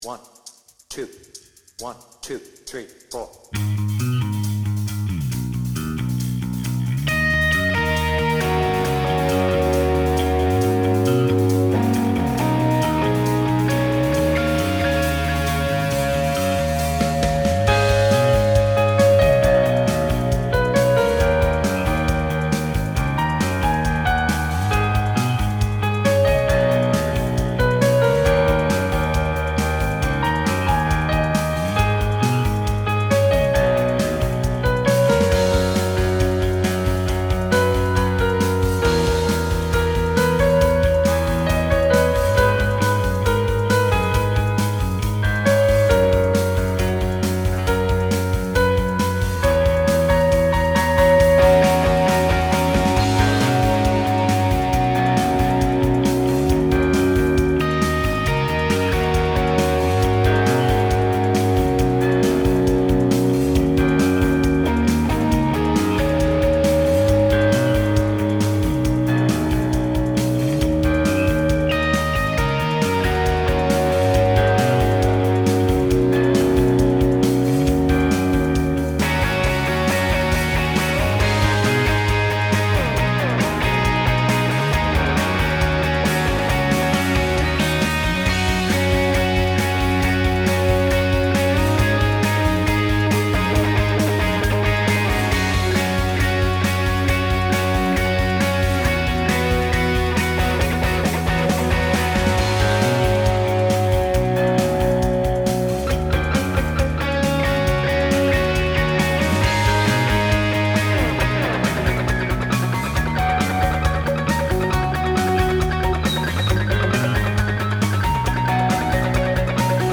BPM : 136
Tuning : Eb